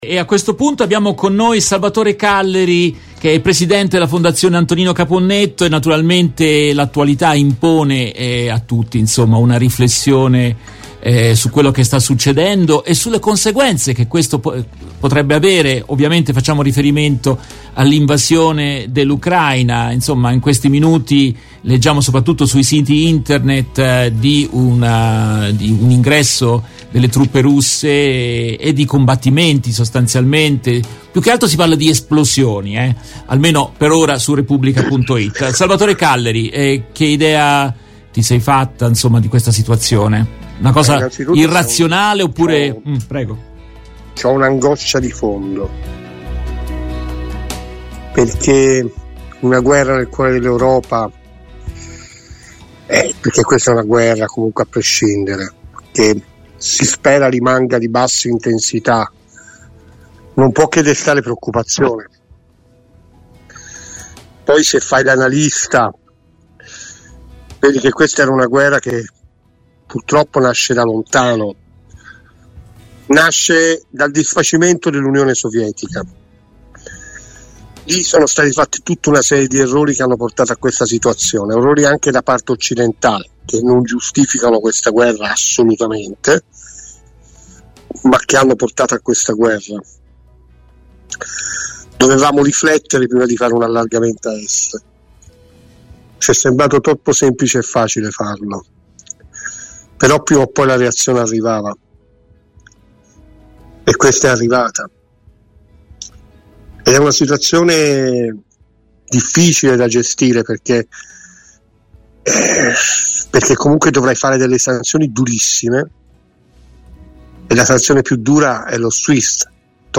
In questa intervista tratta dalla diretta RVS del 24 febbraio 2022